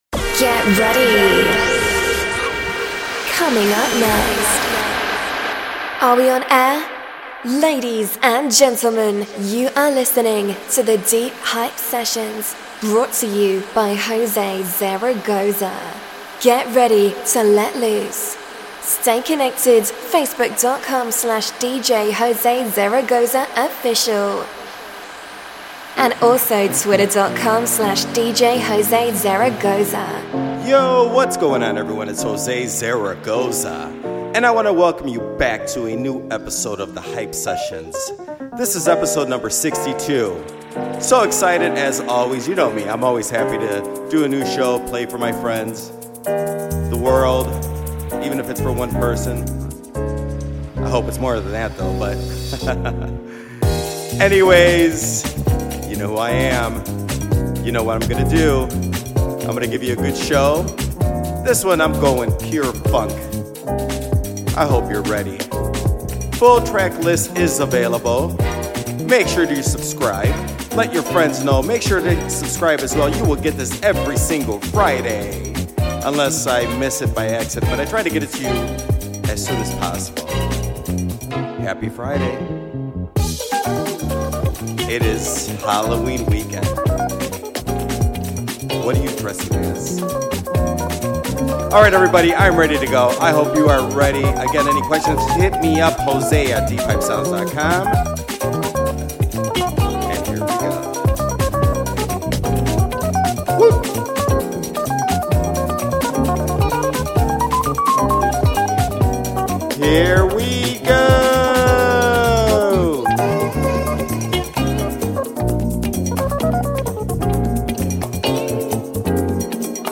The latest show is full of funk!